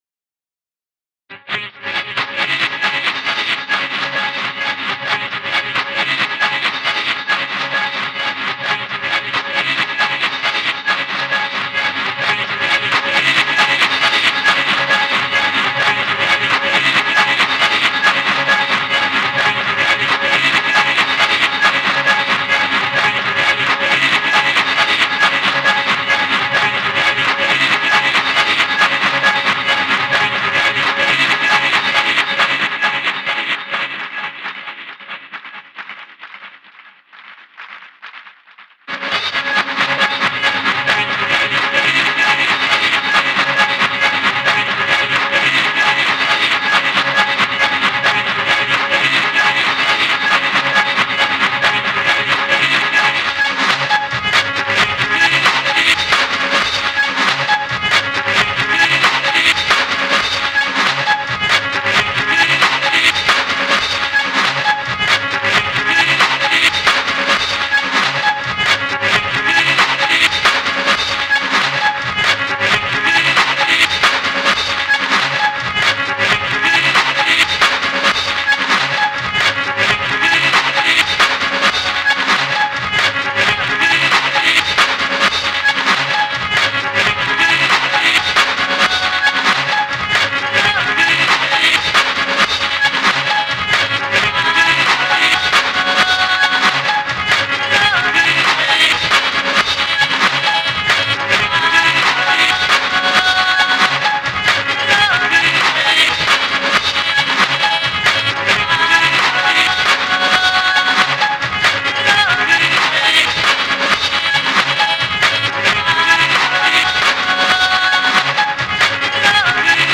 Drones, repeated patterns, pagan rhythms and world grooves.